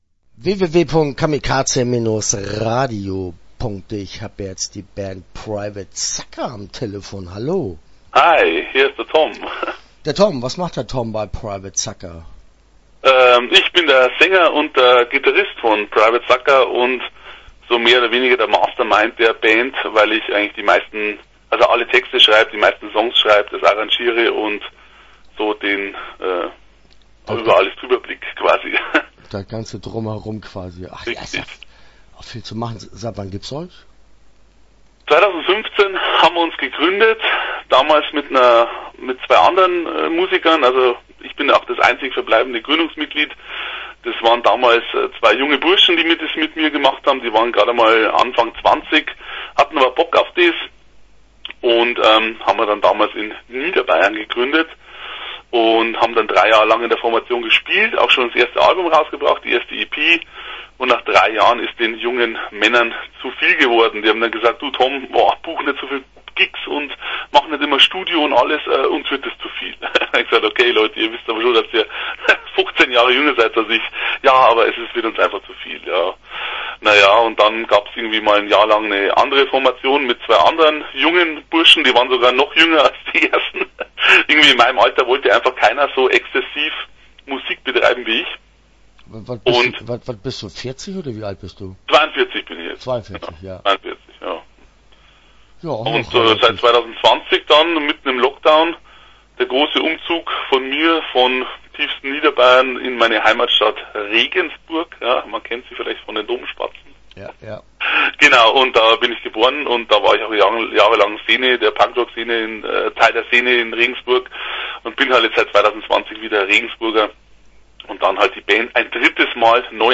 Private Sucker - Interview Teil 1 (12:57)